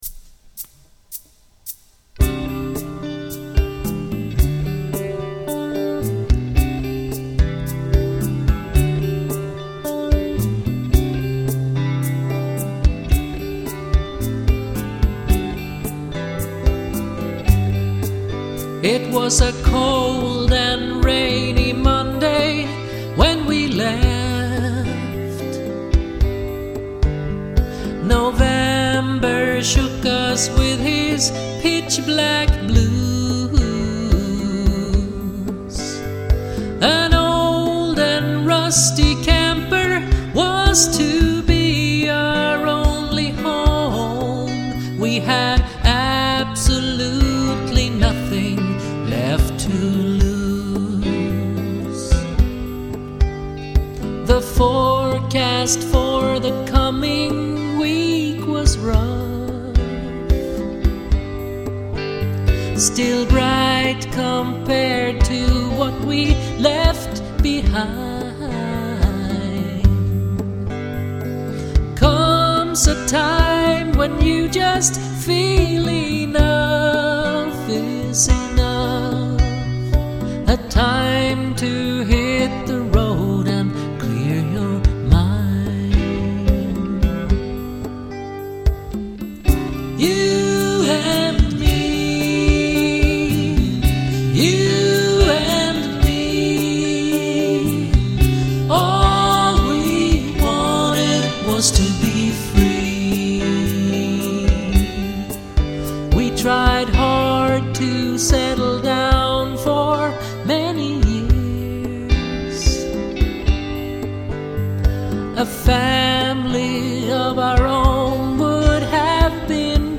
Ljudvolymerna varierar kraftigt mellan de olika spåren.